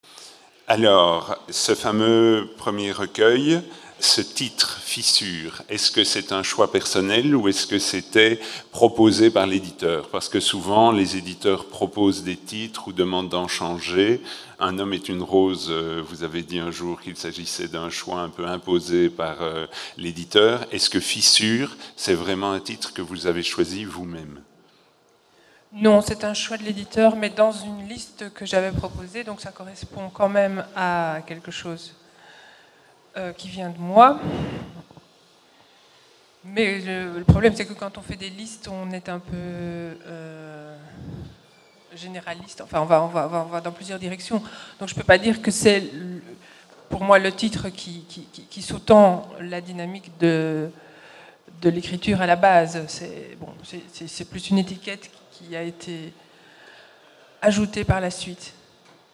entretien
un dimanche devant un public nourri